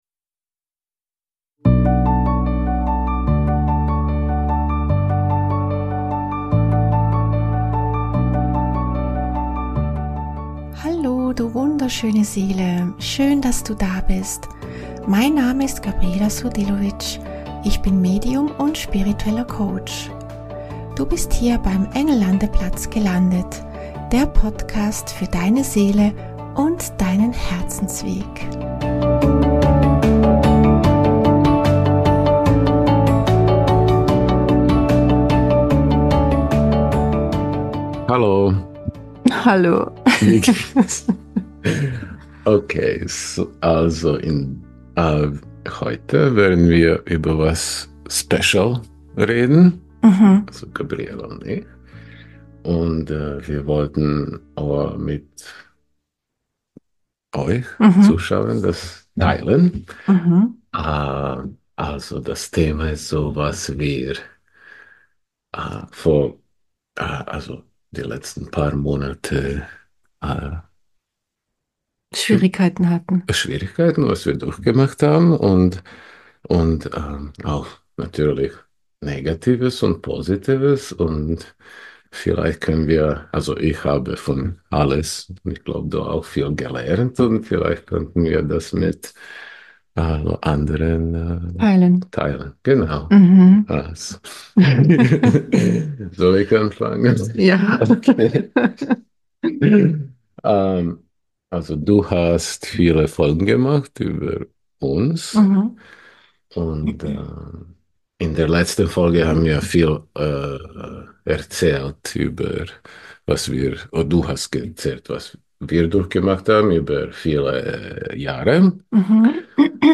In dieser Folge habe ich wieder einmal einen Gast zu Besuch beim Engel Landeplatz. Es ist eine besondere Folge, denn meine Dualseele und ich sprechen über die vergangene Zeit, die nicht ganz einfach war.